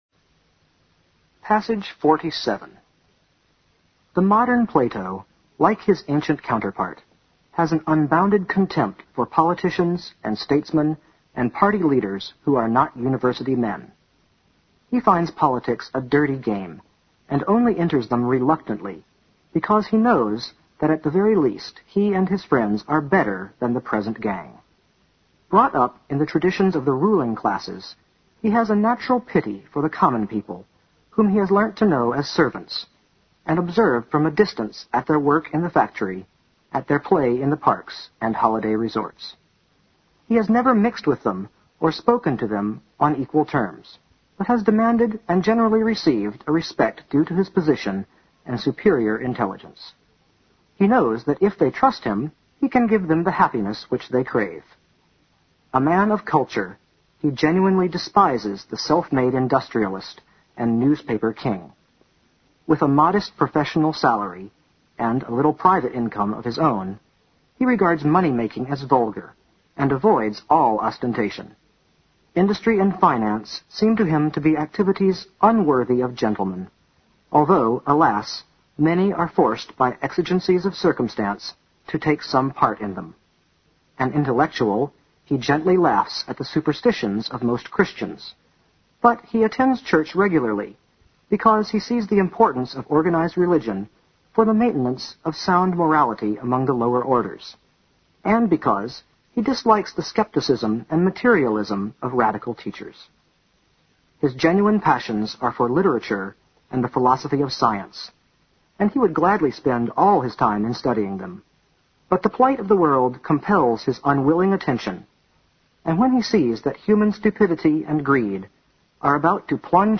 新概念英语85年上外美音版第四册 第47课 听力文件下载—在线英语听力室